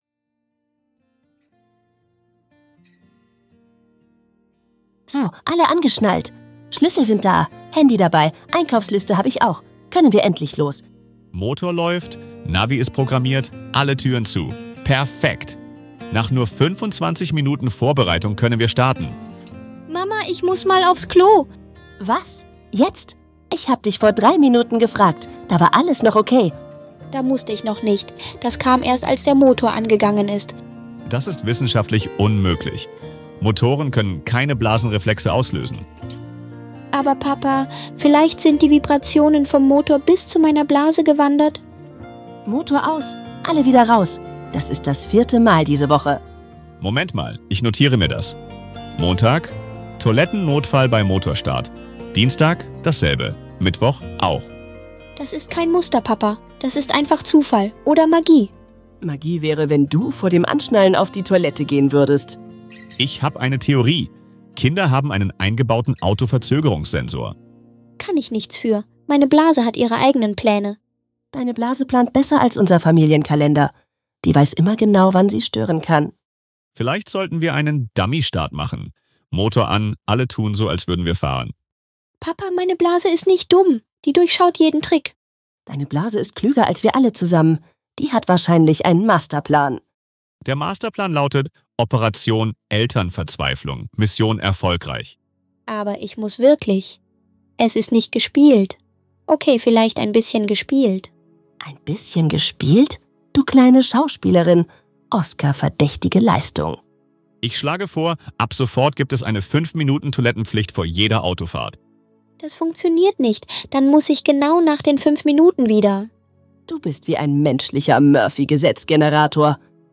In diesem witzigen Gespräch zwischen Mama,